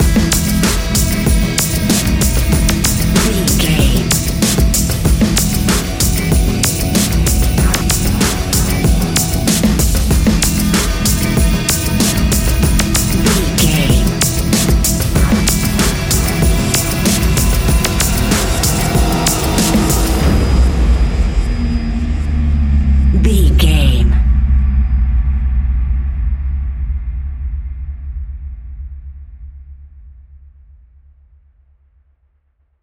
Fast paced
In-crescendo
Ionian/Major
C♯
industrial
dark ambient
EBM
drone
synths
Krautrock